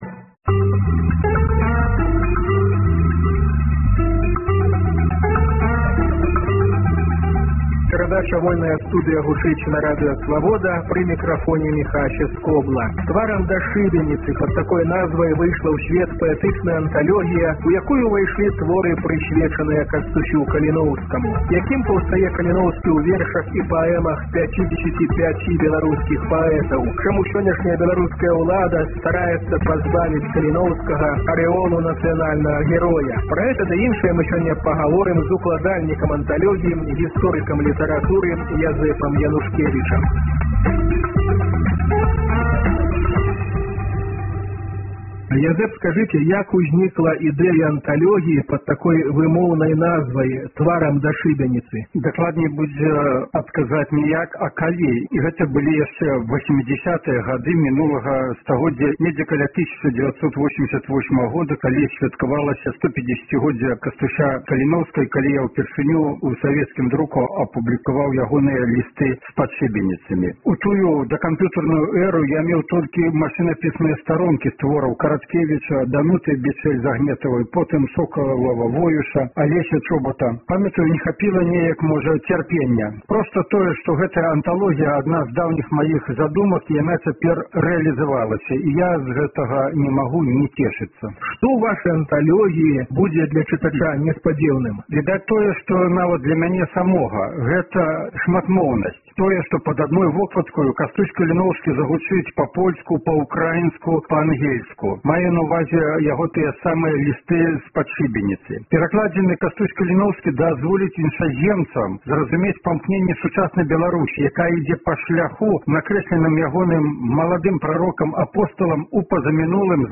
Гутаркі